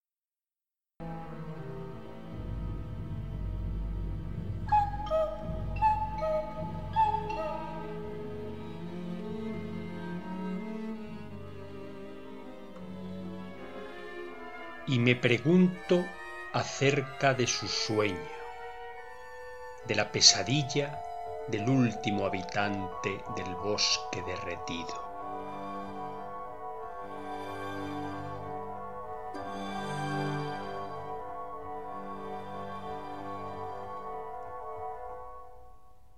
bosque-derretido-y-musica.mp3